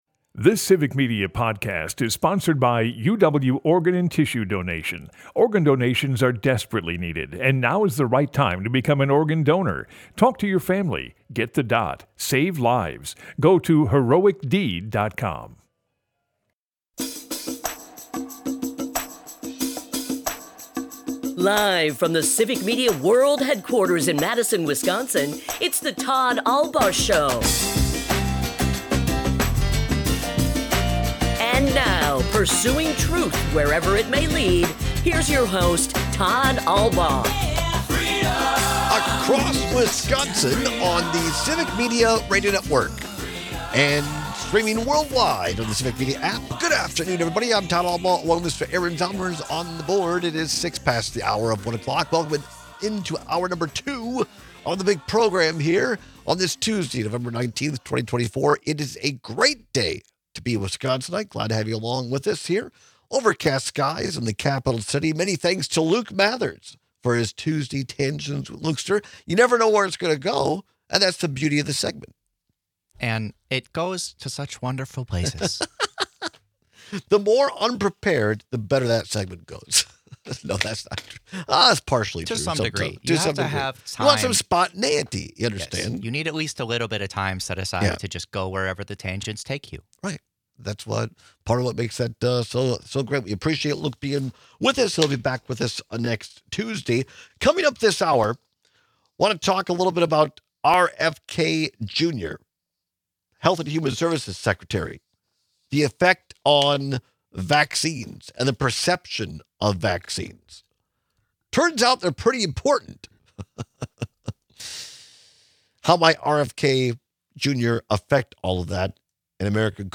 Broadcasts live 12 - 2p across Wisconsin.
11/19/2024 Listen Share In today’s second hour, we take some of your calls on Trump’s media intimidation tactics.